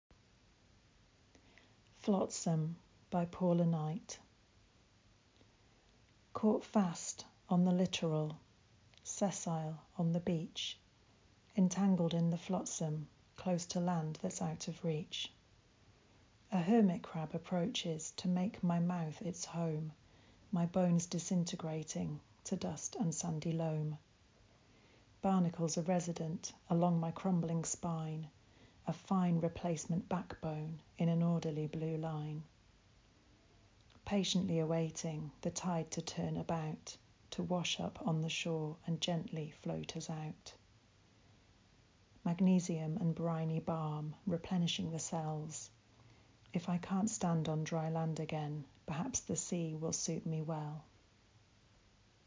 Click to hear this poem out loud.